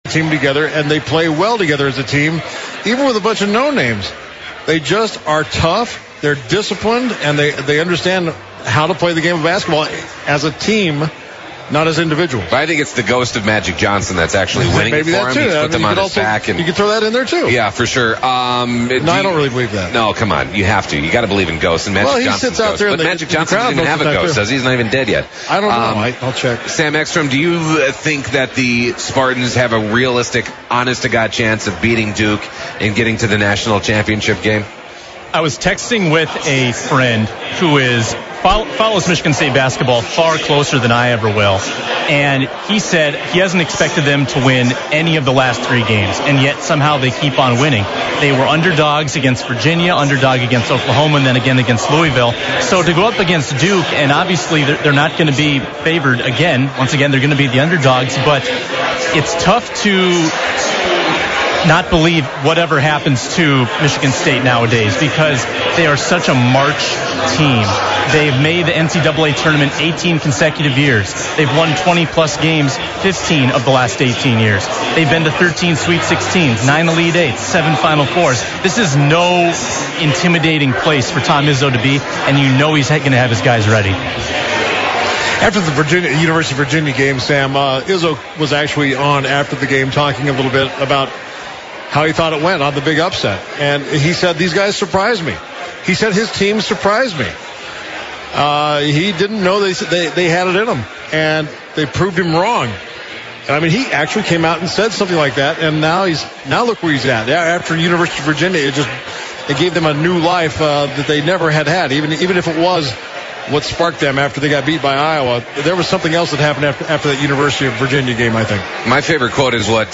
The guys also talk with fans about Minnesota's lack of sports championship victories.